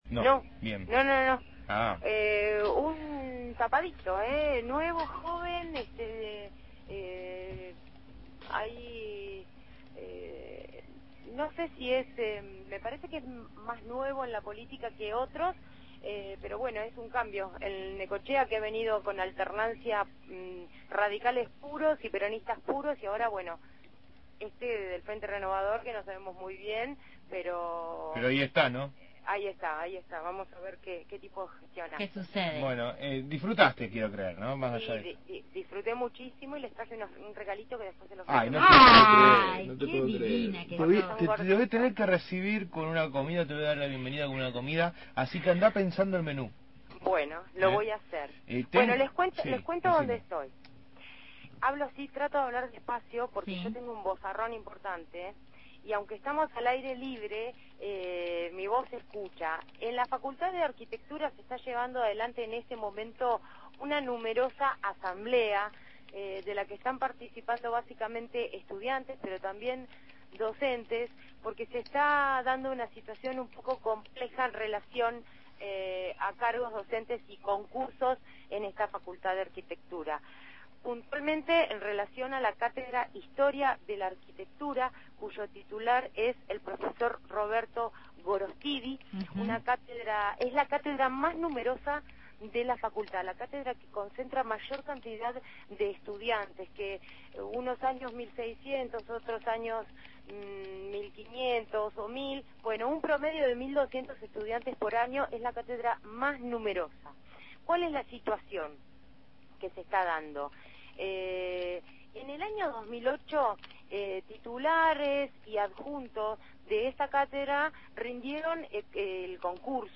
MÓVIL/ Asamblea en Fac. de Arquitectura – Radio Universidad